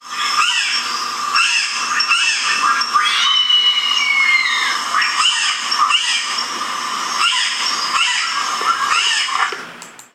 1. Harpy eagles (Harpia harpyja) audio recordings from WikiAves: